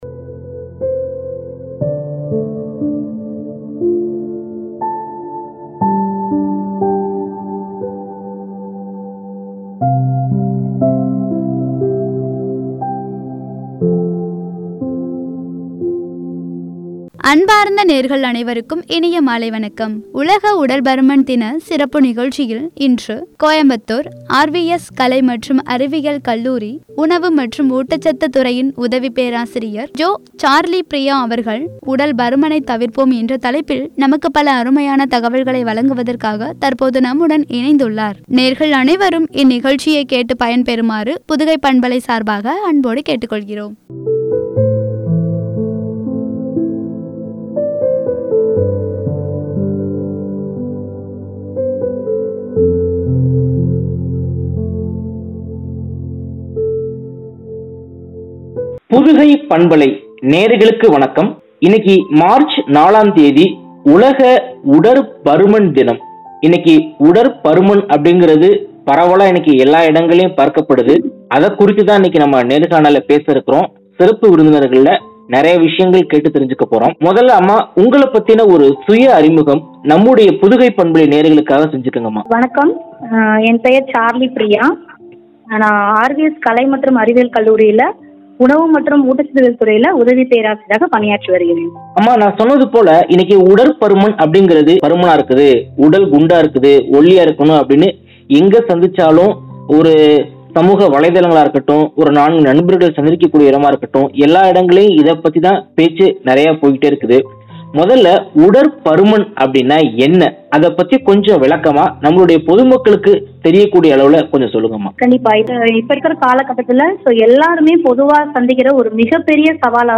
“உடல் பருமனை தவிர்ப்போம்” என்ற தலைப்பில் வழங்கிய உரையாடல்.